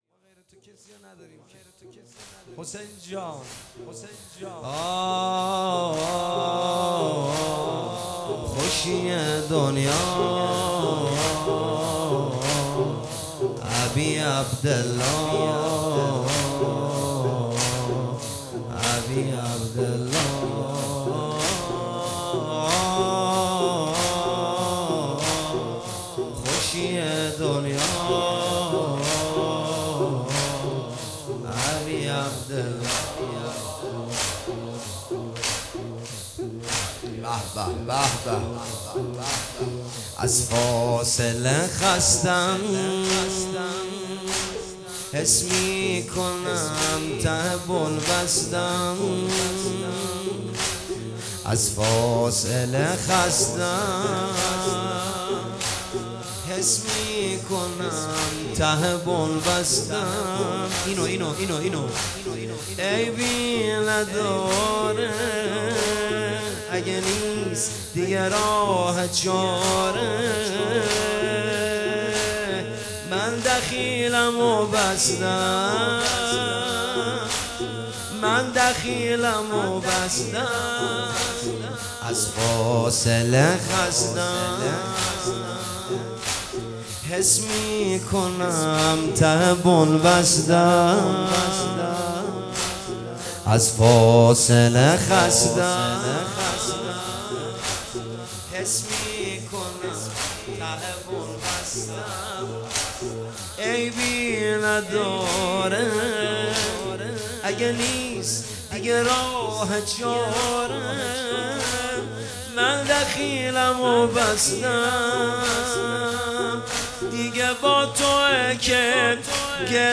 جلسه هفتگی اردیبهشت 1404